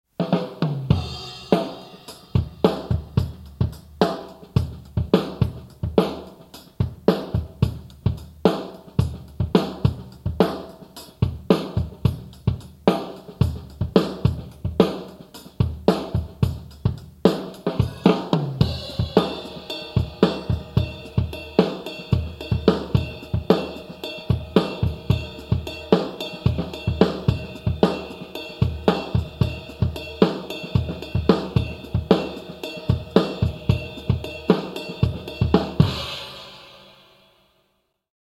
A straight-time rock groove with a funk feel.
This groove was composed spontaneously at the drum kit.
1 + 2 + 3 + 4 + 1 + 2 + 3 + 4 + 1/4 = 110 bpm 4 x x x x x x x x x x x x x x x x - O O O o O 4 o o o o o o o o The simple change here is in the second measure: the snare is permutated one-eighth to the right, playing on the ands of 2 and 4. Ghost notes, not transcribed here, fill up the spaces and propel the groove. Listen to the MIDI or MP3 files for a quarter-note ride interpretation, which has a less subtle, more cutting feel.